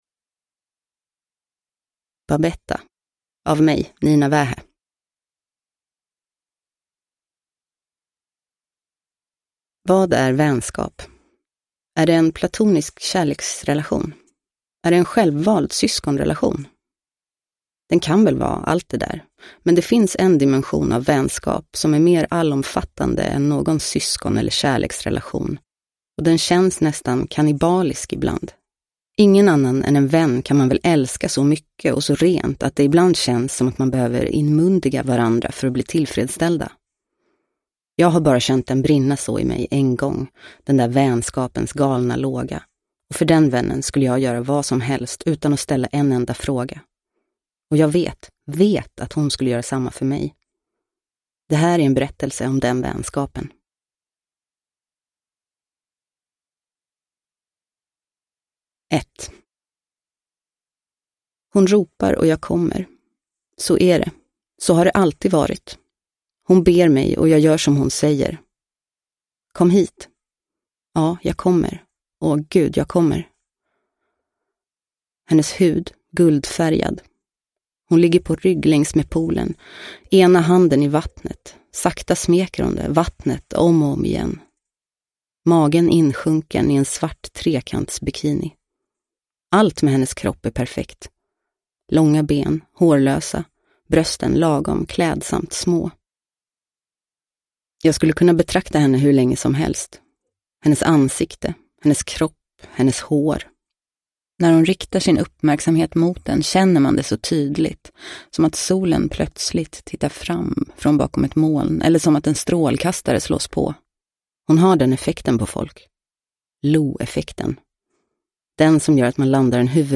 Babetta – Ljudbok – Laddas ner
Uppläsare: Nina Wähä